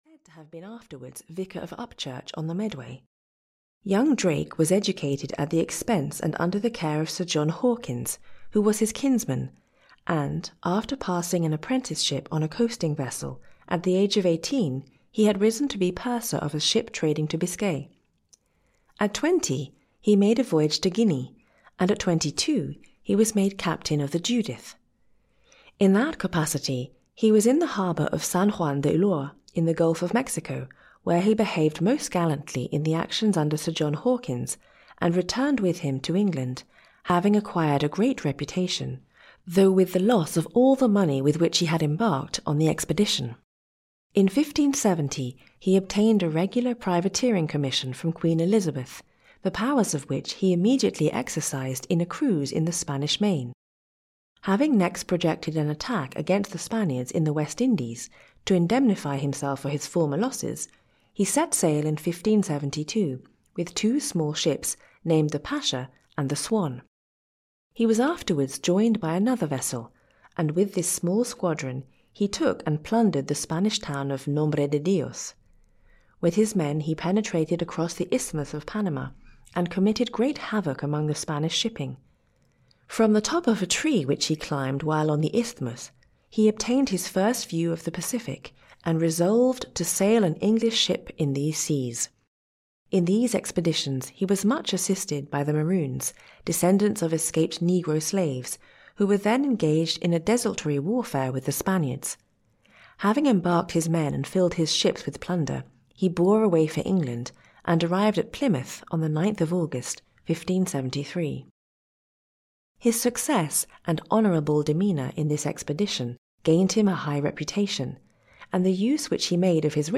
5 Famous English Historical Figures (EN) audiokniha
Ukázka z knihy